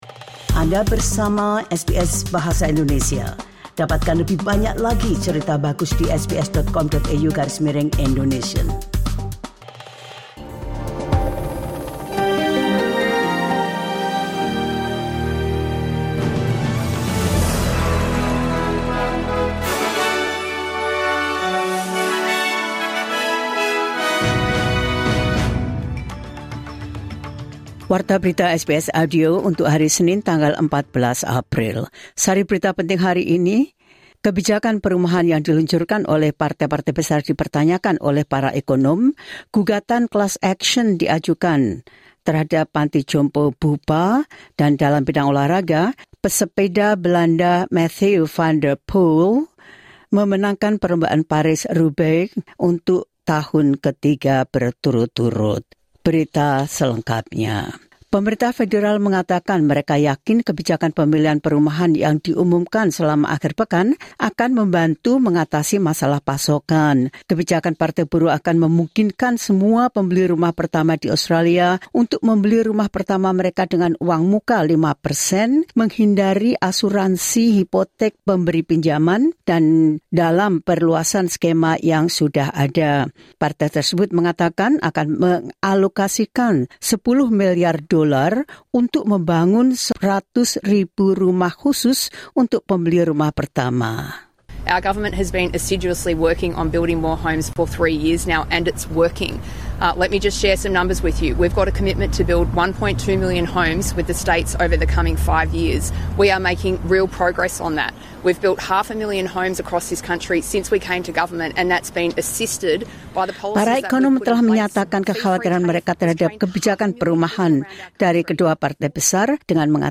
Latest News SBS Audio Indonesian Program – 14 Apr 2025.